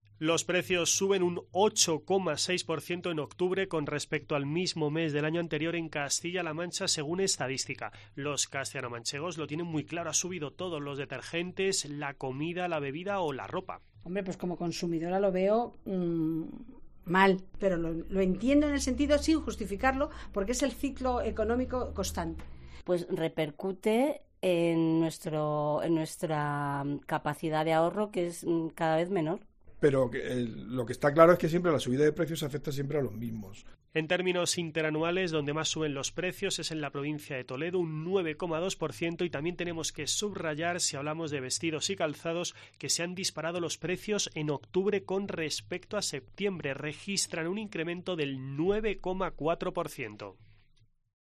En la parte superior de esta noticia puedes encontrar la crónica